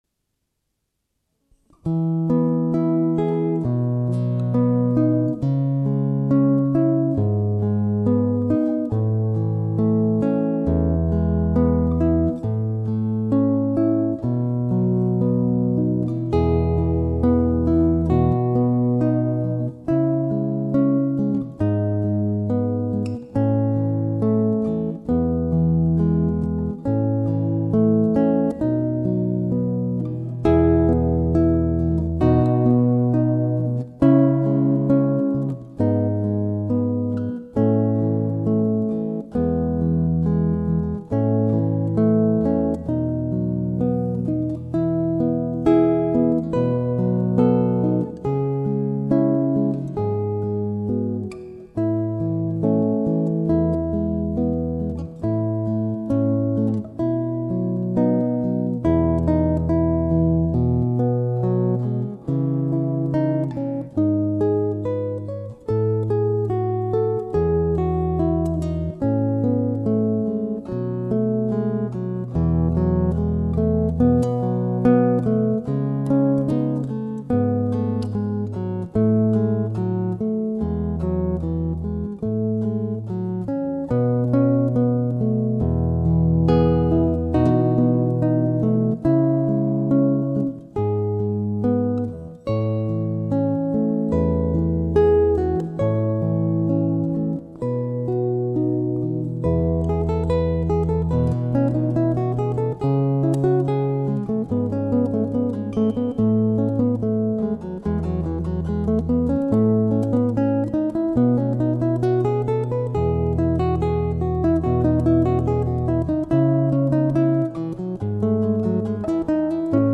classical guitarist